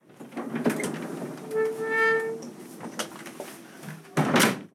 Subir un mueble cama
bisagra
chasquido
chirrido
rechinar
Sonidos: Acciones humanas
Sonidos: Hogar